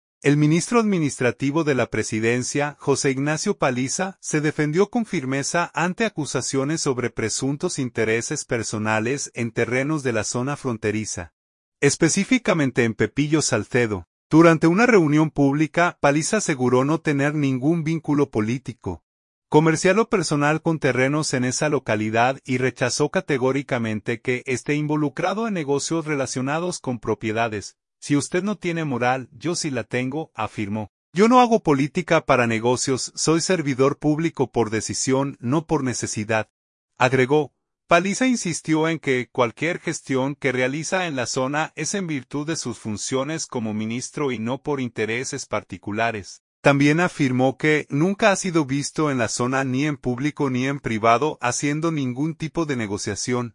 Santo Domingo.- El ministro administrativo de la Presidencia, José Ignacio Paliza, se defendió con firmeza ante acusaciones sobre presuntos intereses personales en terrenos de la zona fronteriza, específicamente en Pepillo Salcedo.
Durante una reunión pública, Paliza aseguró no tener ningún vínculo político, comercial o personal con terrenos en esa localidad y rechazó categóricamente que esté involucrado en negocios relacionados con propiedades.